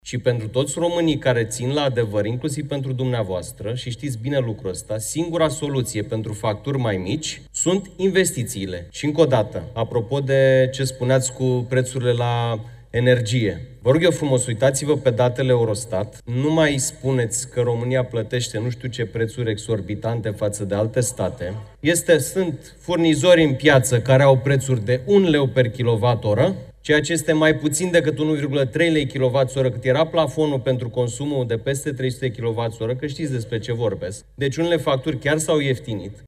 La Parlament, facturile la curent au fost, din nou, în centrul atenției.
O replică a venit de la deputatul liberal Sebastian Burduja, fost ministru al Energiei în guvernul Ciolacu.
Sebastian Burduja, deputat PNL: „Sunt furnizori în piață care au prețuri de un leu per kilowat pe oră”